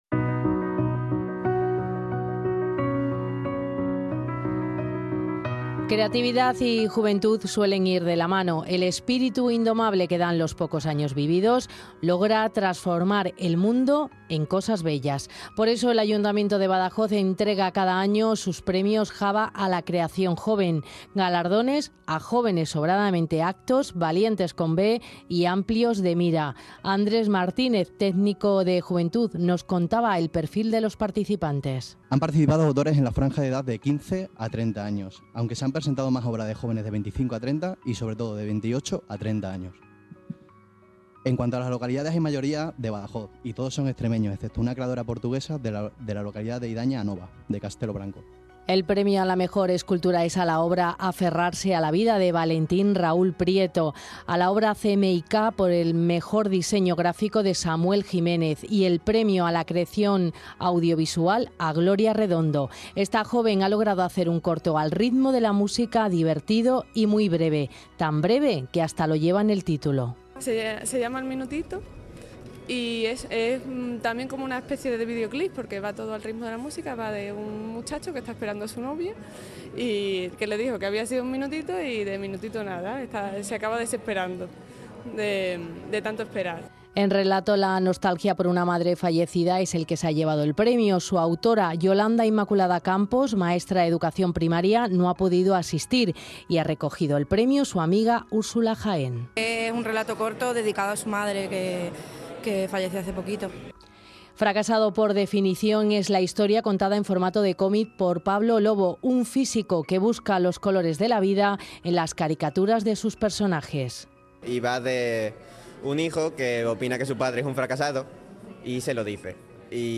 Reportaje Jóvenes Creadores JABA 2014.